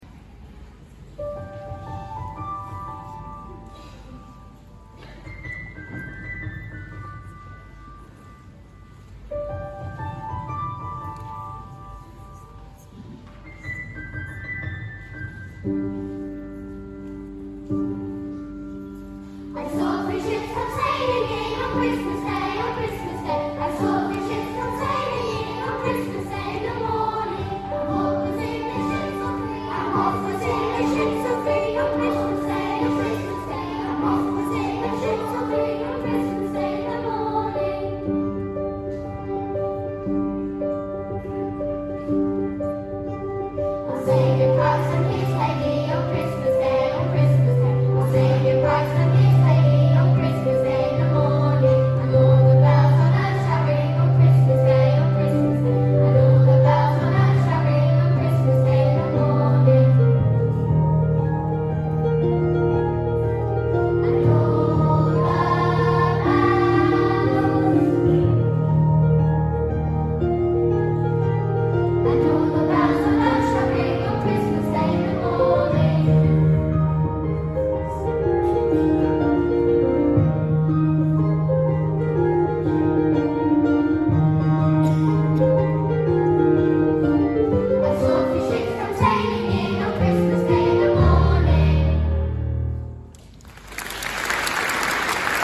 I Saw Three Ships | Y4, 5 & 6 Choir